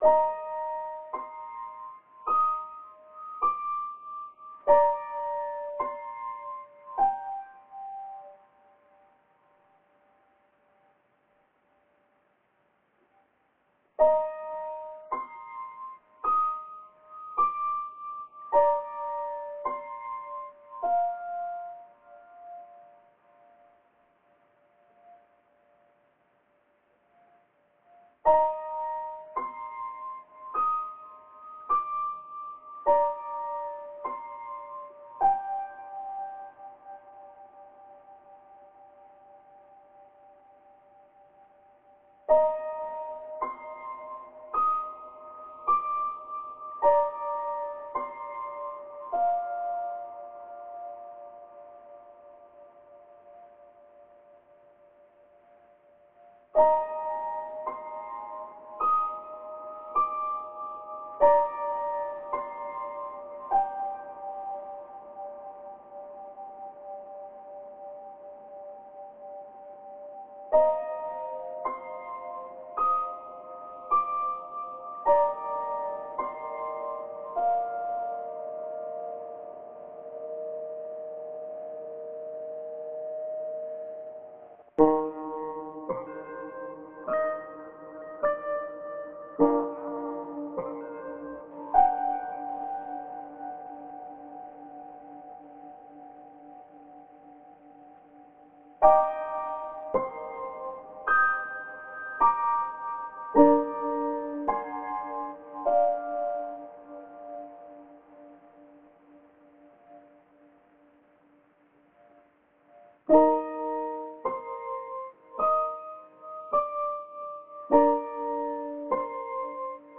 Just a bit of piano recorded on my phone and eddited up